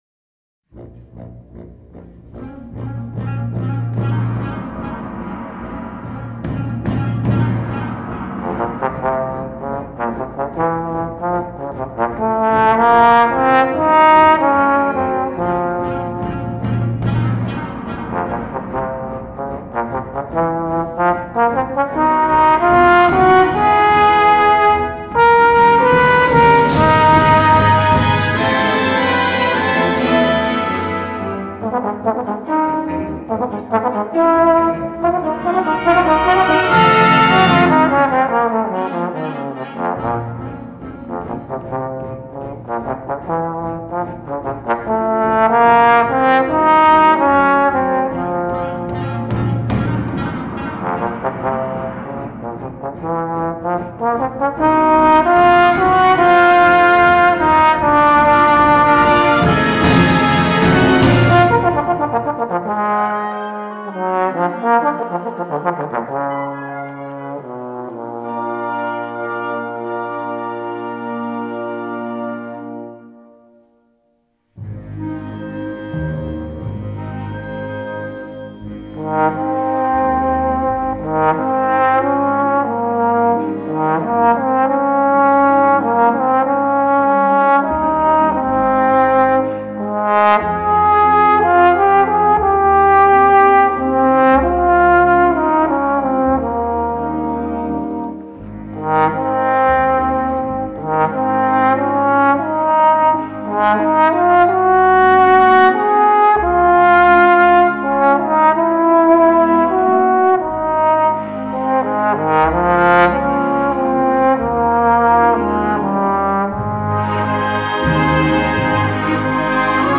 Gattung: für Posaune und Klavier